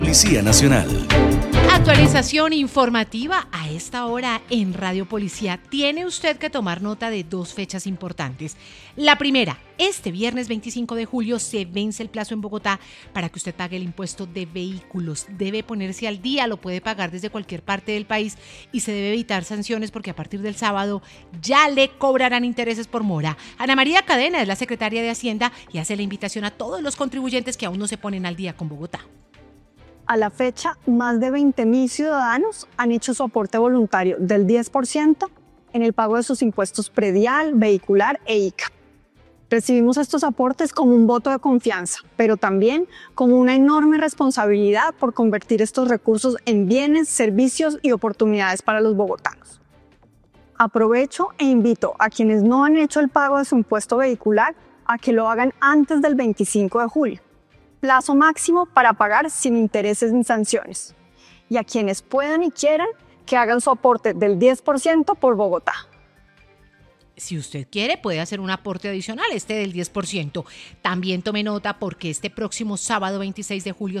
Emisora de la Policía Nacional